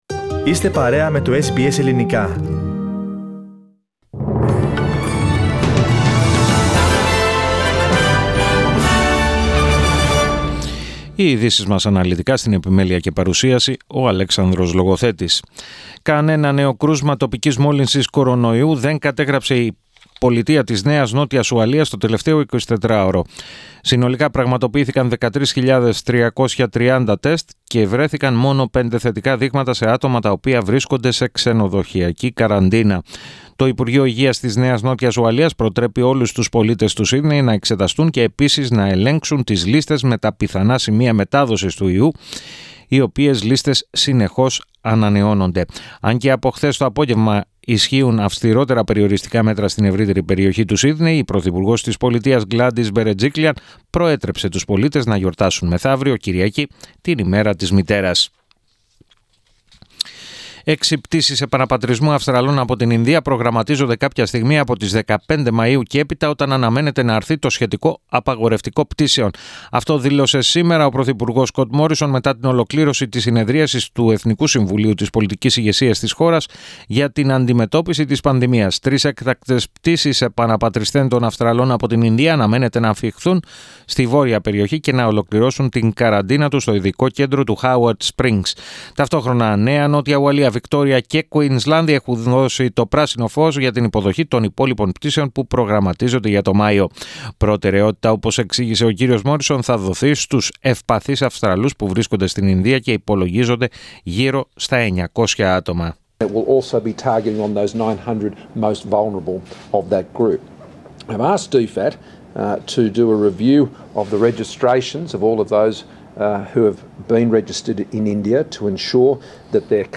Ειδήσεις 07.05.21
Το αναλυτικό δελτίο στις 16:00